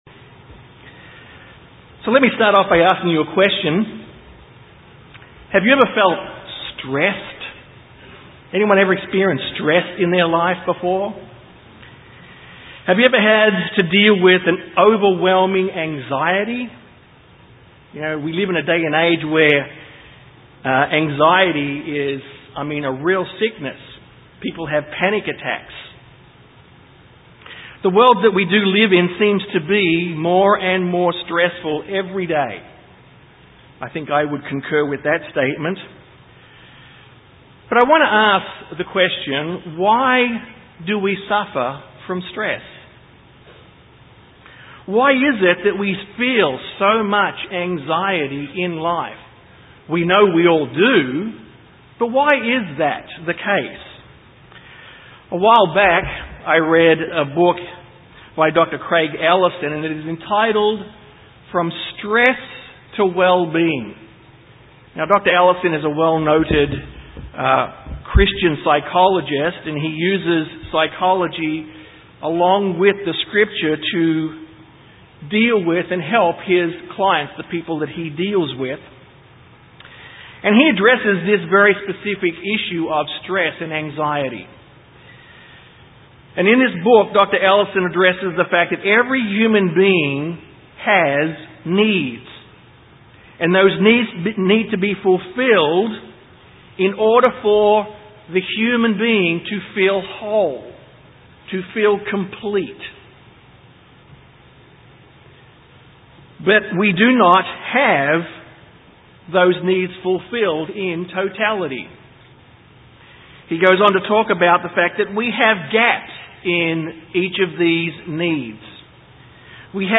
Man was created with 8 "Ontological Givens" or Qualities of Life. God created Adam & Eve in Wholeness and Completeness in these Qualities. This sermon focuses on what those 8 Qualities of Life are and how they are seen in the lives of Adam and Eve.